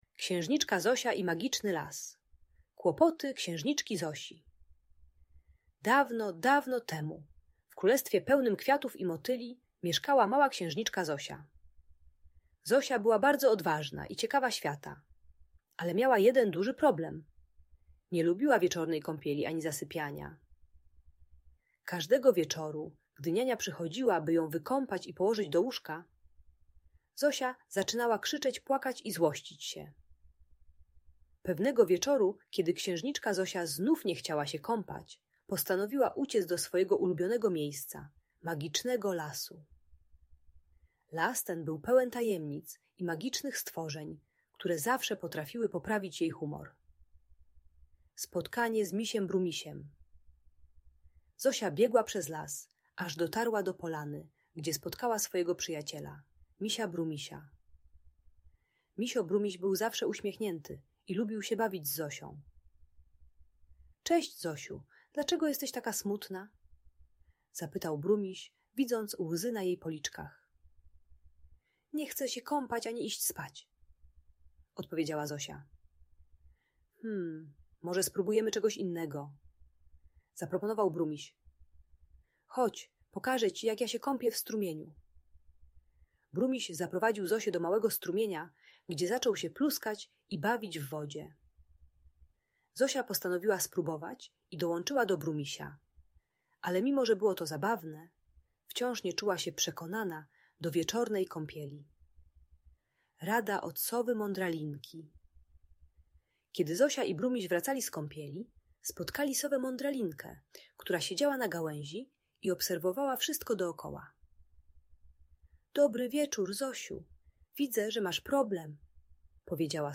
Opowieść o Księżniczce Zosi i Magicznym Lesie - Audiobajka dla dzieci